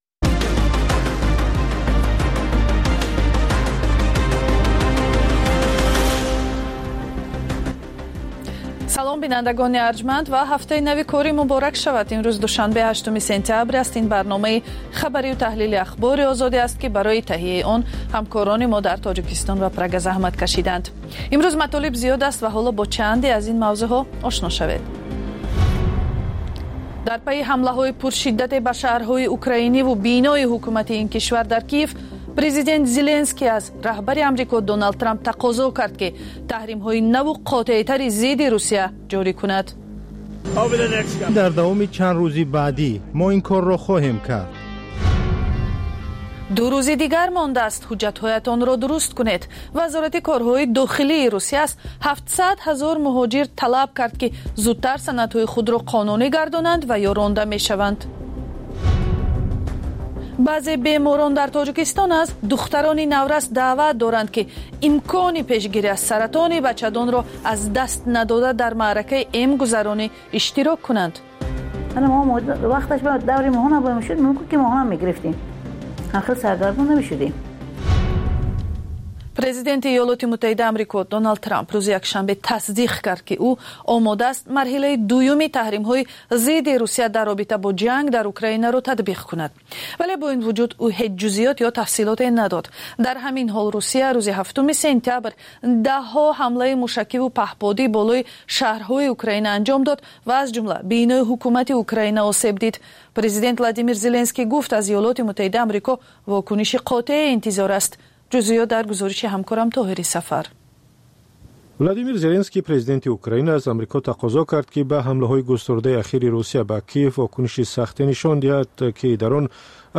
Пахши зинда